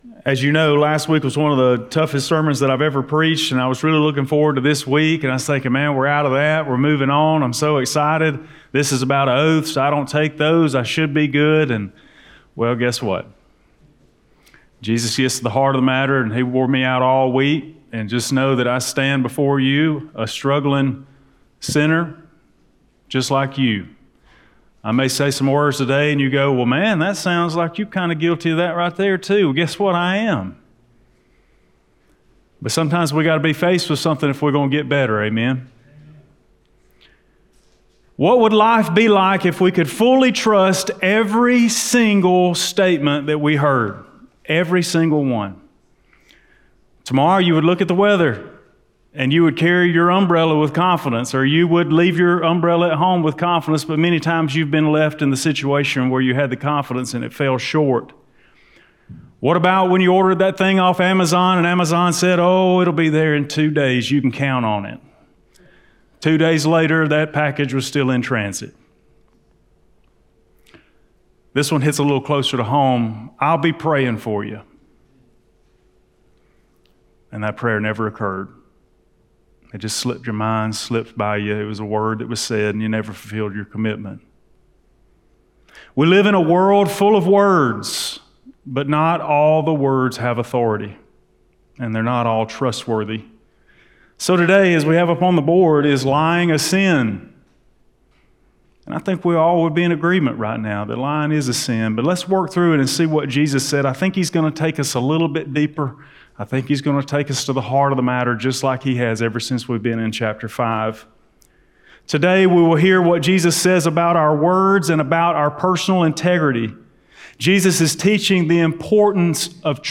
Blount Springs Baptist Church Sermons Is Lying A Sin?